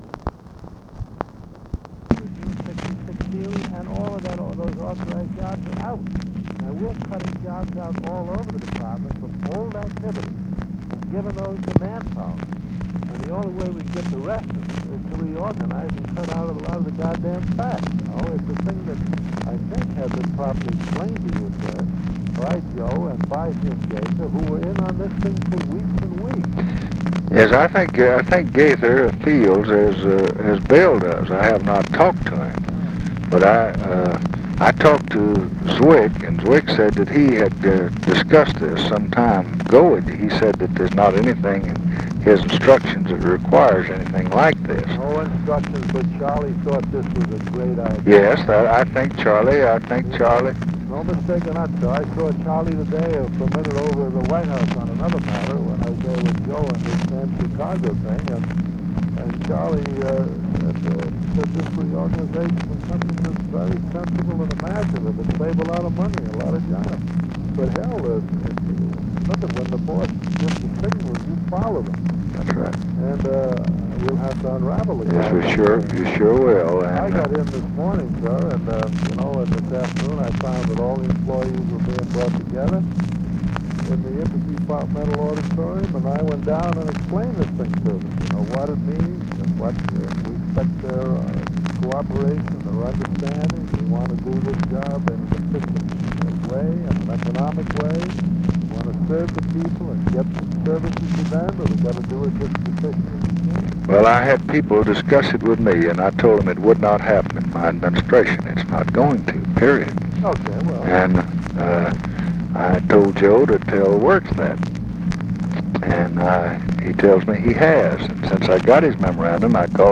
Conversation with JAMES REYNOLDS, October 23, 1968
Secret White House Tapes